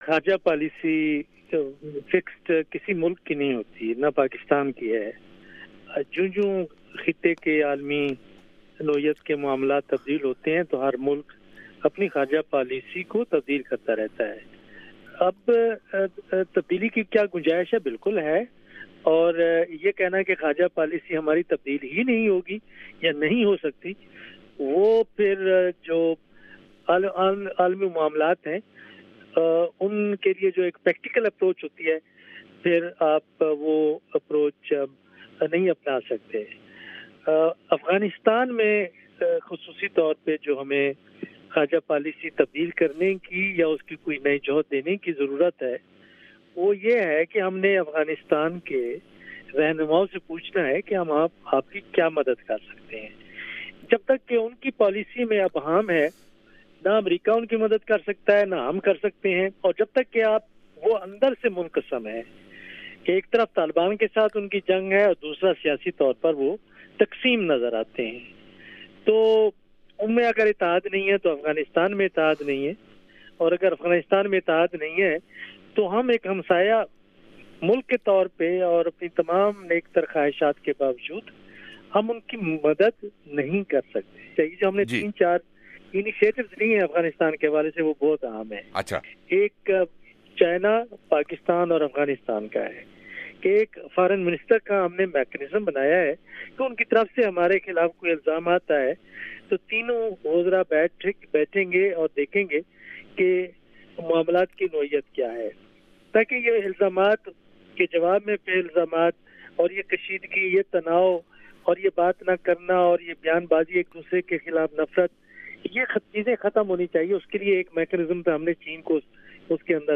وائس آف امریکہ کے پروگرام جہاں رنگ میں پاکستان کی خارجہ پالیسی کے حوالے سے ایسے میں گفتگو کی گئی۔